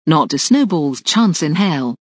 snowball.mp3